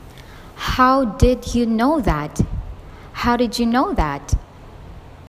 當單字的語尾爲「d」，而下一個單字的語首是「y」時，則會發成「dʒú」的音。
did⌒you　              didyu